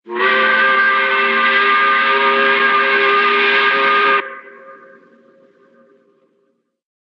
Whistle Sound Effects MP3 Download Free - Quick Sounds